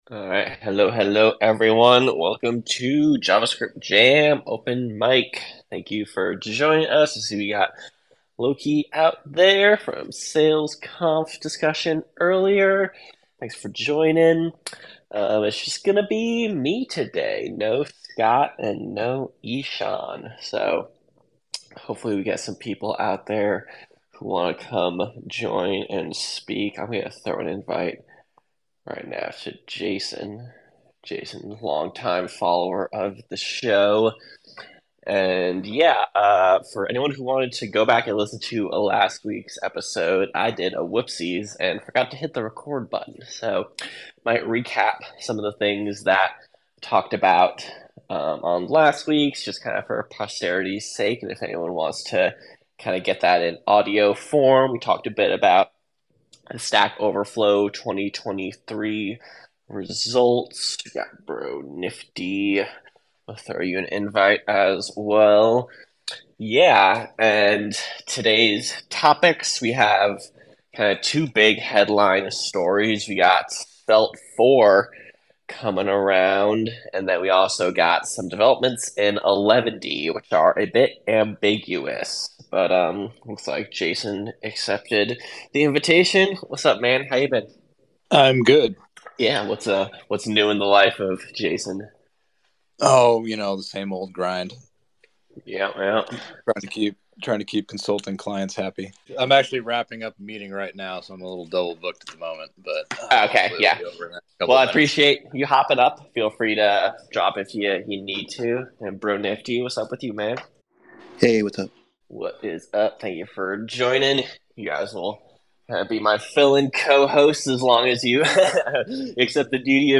An open mic chat covers RedwoodJS, Svelte 4, Eleventy changes, Next.js complexities, and the continuing evolution of JS frameworks in modern web development